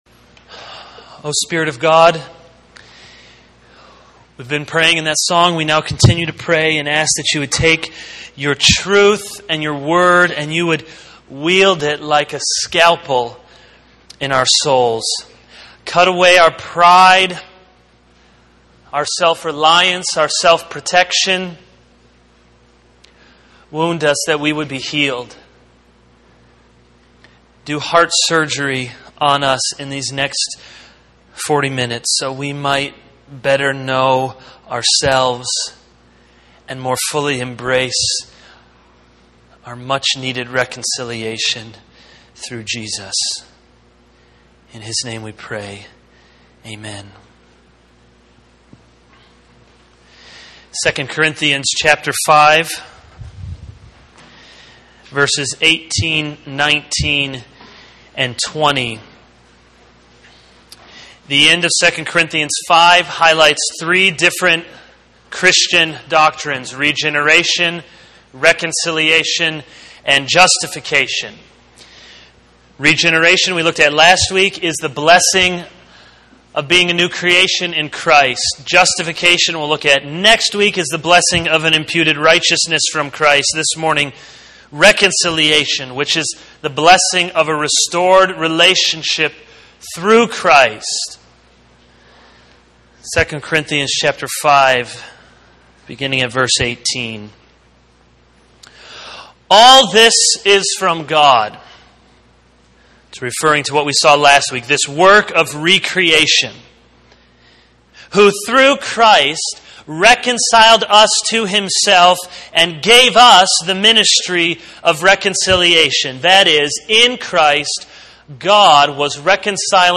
This is a sermon on 2 Corinthians 5:18-20.